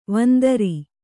♪ vandari